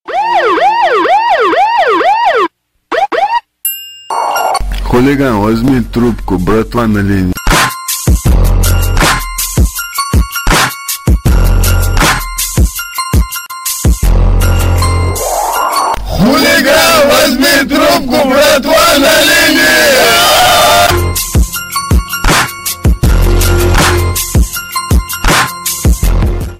• Качество: 320, Stereo
громкие
mash up
полицейская сирена